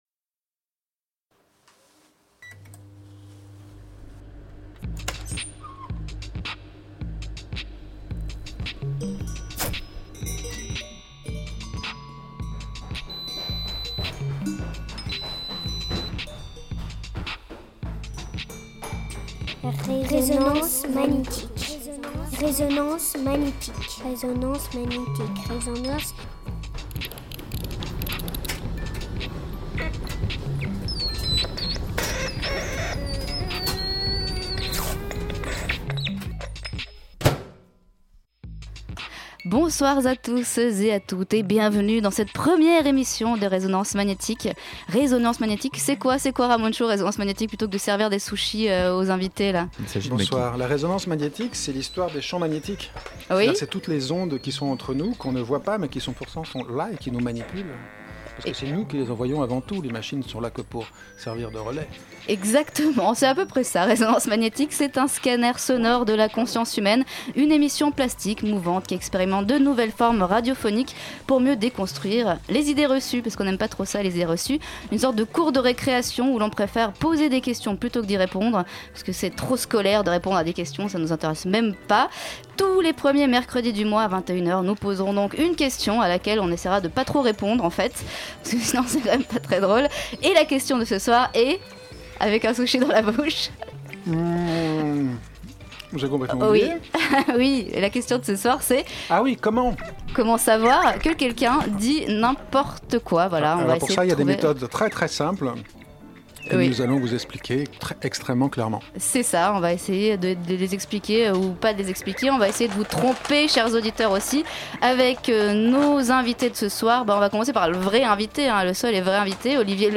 Résonance Magnétique 07/10/2015 Résonance Magnétique est un scanner sonore de la conscience humaine. Une émission plastique, mouvante, qui expérimente de nouvelles formes radiophoniques pour mieux déconstruire les idées reçues.